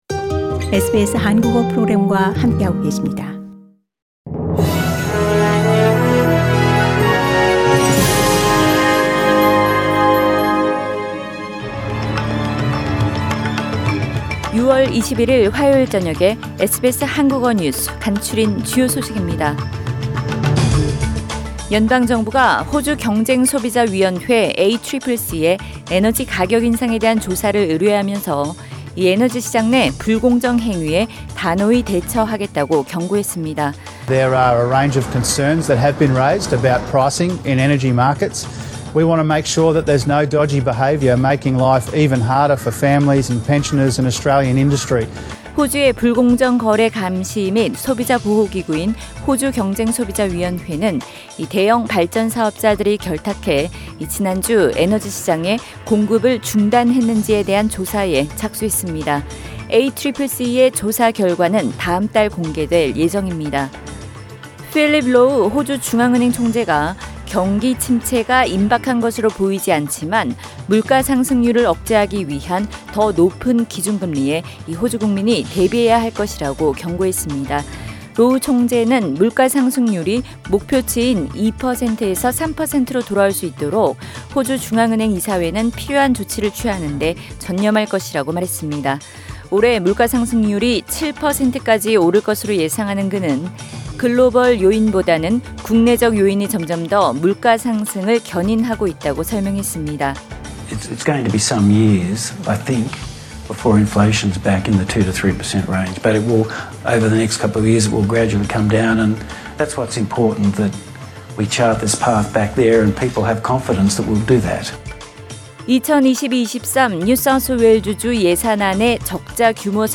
2022년 6월 21일 화요일 저녁 SBS 한국어 간추린 주요 뉴스입니다.